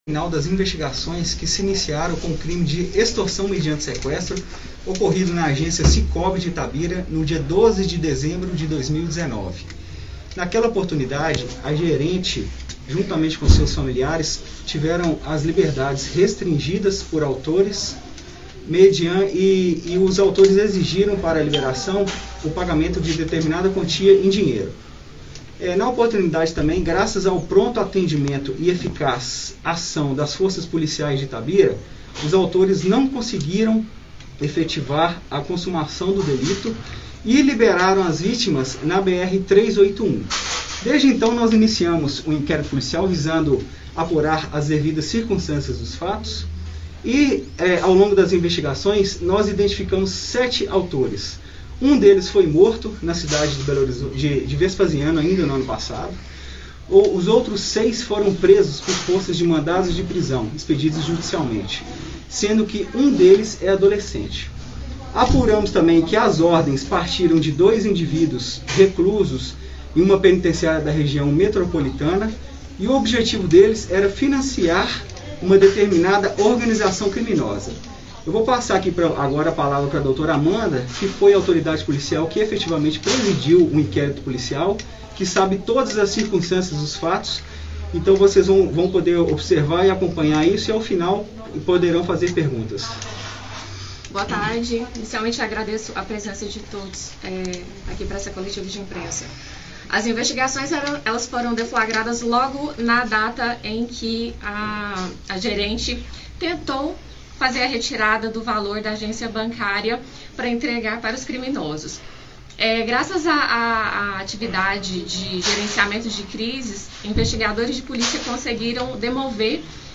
Coletiva-Crime-do-Sapatinho.mp3